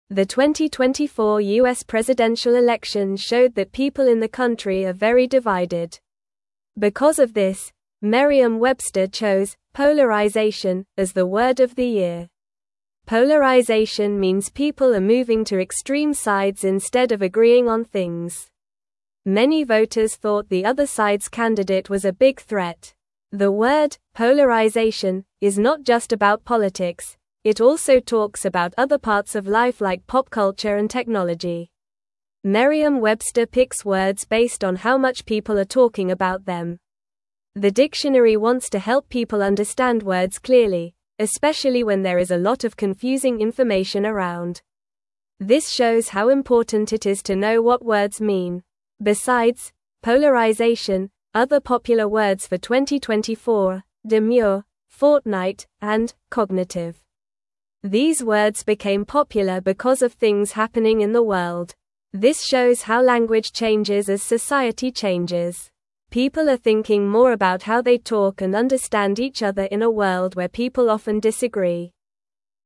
Normal
English-Newsroom-Lower-Intermediate-NORMAL-Reading-People-Are-Divided-The-Word-of-the-Year.mp3